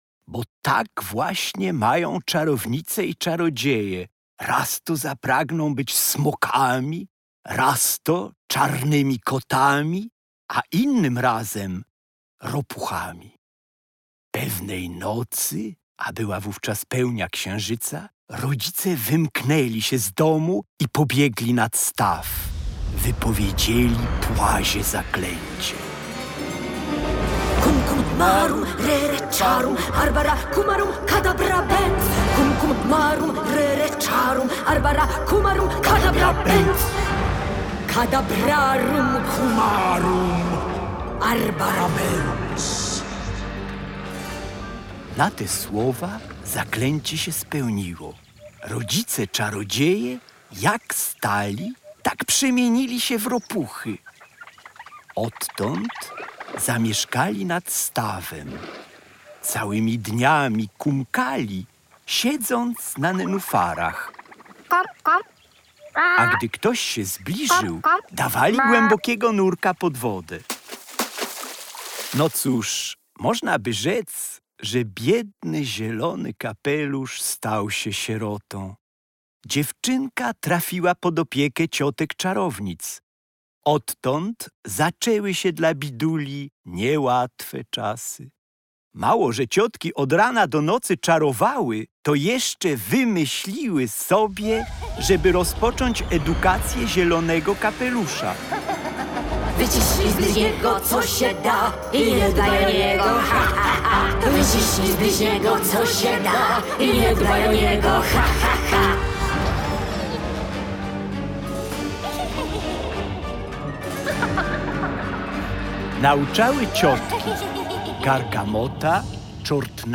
Zielony kapelusz - audiobook w postaci słuchowiska z piosenkami (mp3)